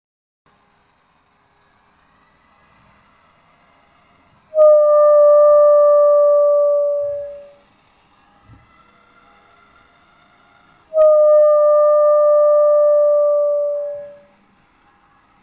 Hält man nun das Glas kurz über einen Bunsenbrenner und erhitzt das Sieb bis es glüht, so entsteht ein schöner, klarer Ton wie bei einer Orgelpfeife.